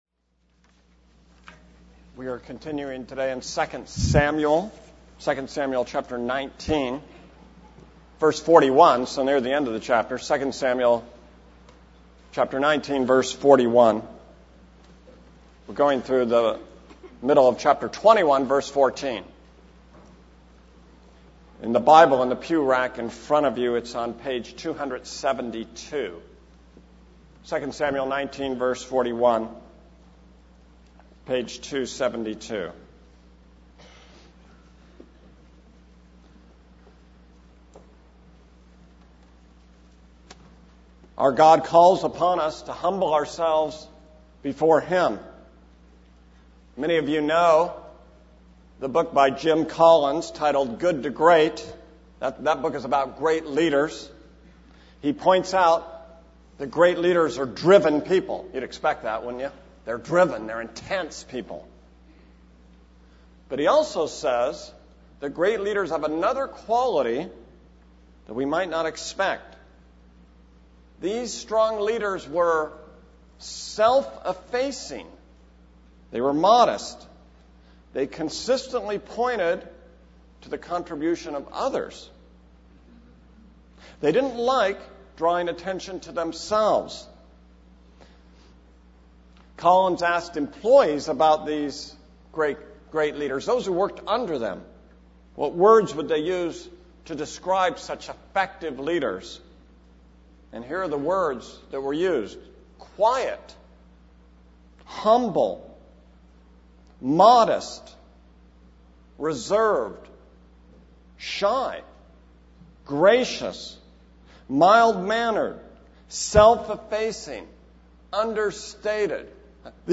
This is a sermon on 2 Samuel 19:41-21:14.